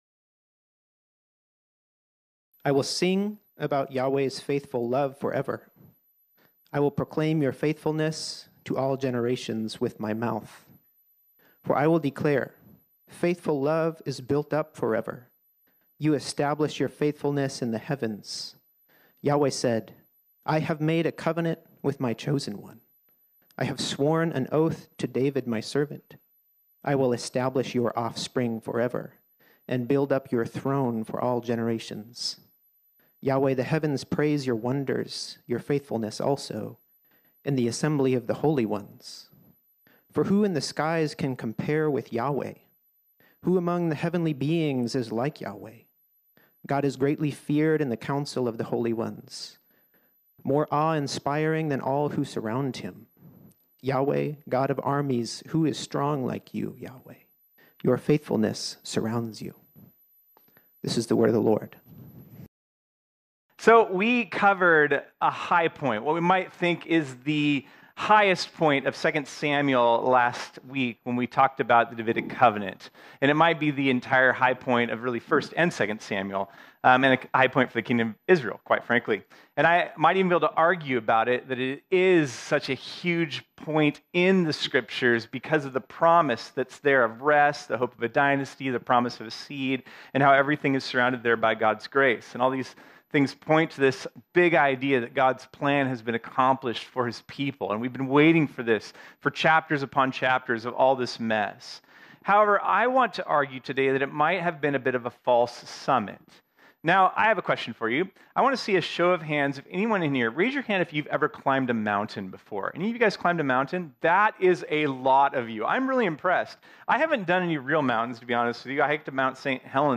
This sermon was originally preached on Sunday, August 13, 2023.